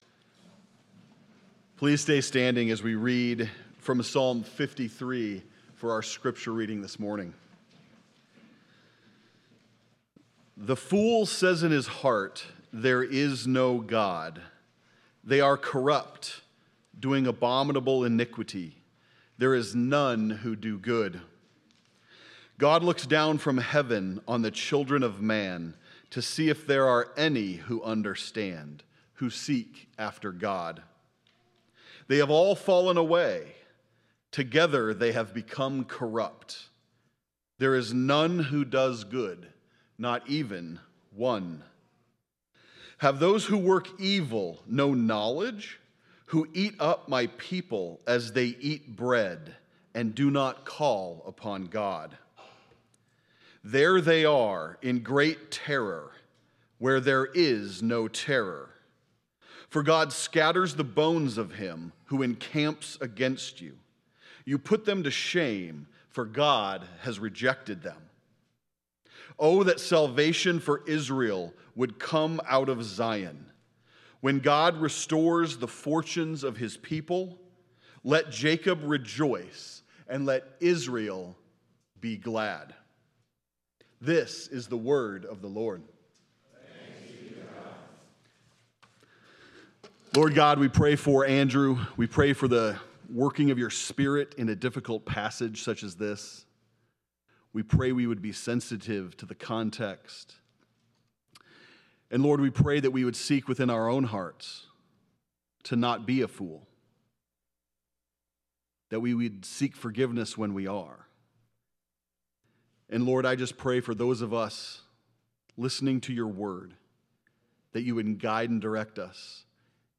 7.6.25 sermon.m4a